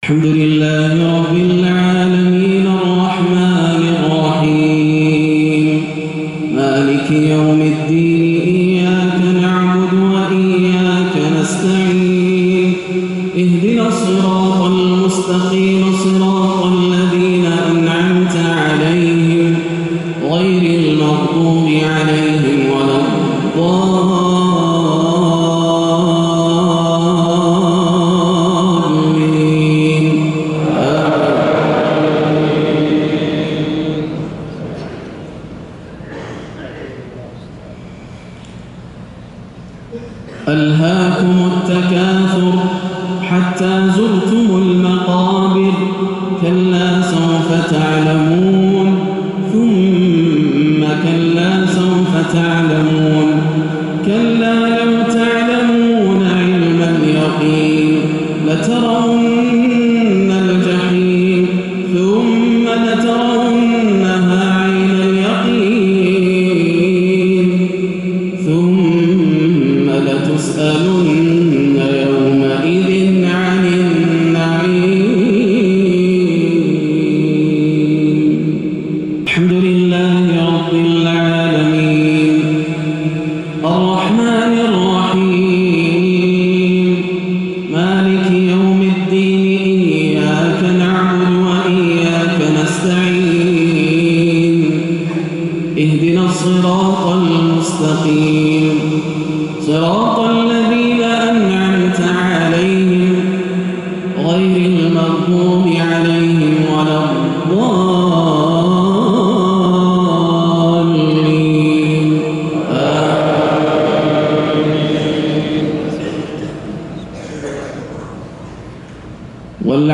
صلاة الجمعة 1-7-1437هـ سورتي التكاثر و العصر > عام 1437 > الفروض - تلاوات ياسر الدوسري